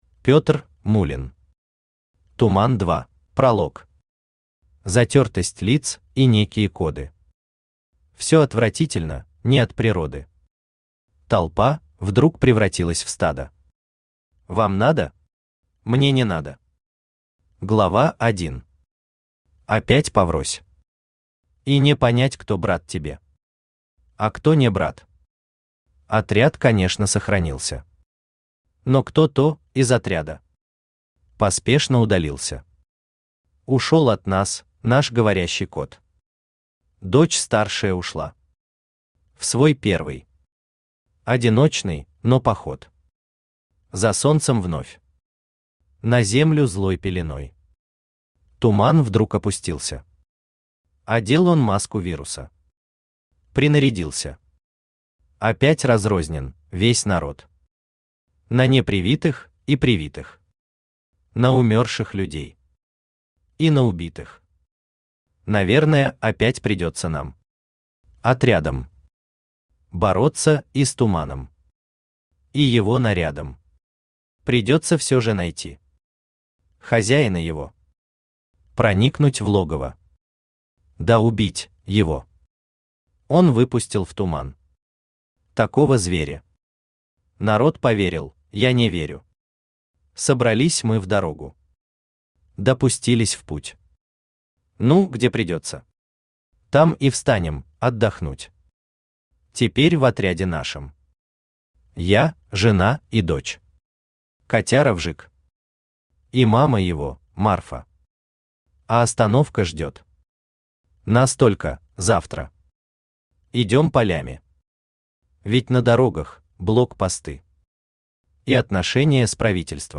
Аудиокнига Туман 2 | Библиотека аудиокниг
Aудиокнига Туман 2 Автор Пётр Гамильтонович Муллин Читает аудиокнигу Авточтец ЛитРес.